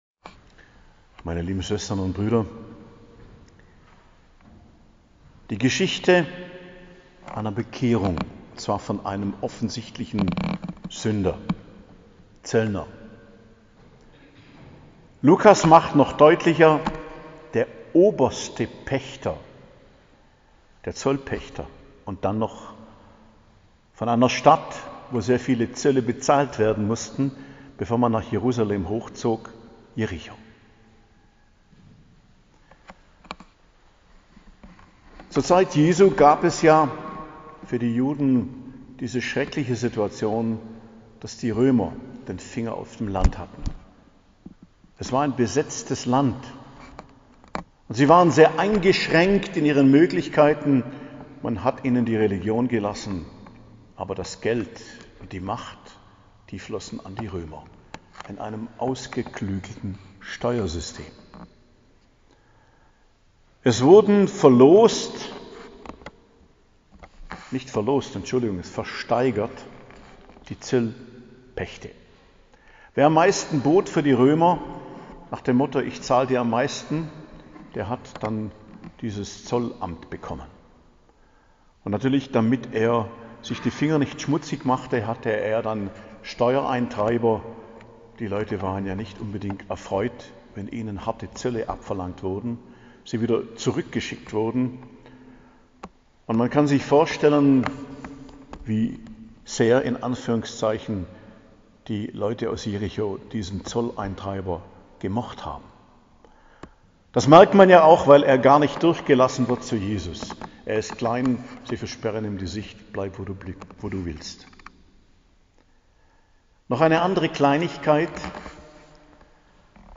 Predigt zum 31. Sonntag i.J., 30.10.2022